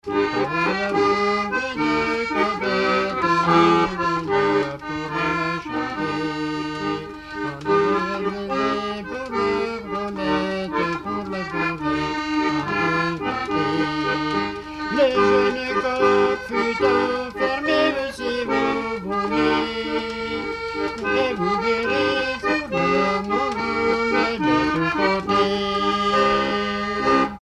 Brizon
Pièce musicale inédite